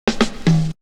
Break 27.wav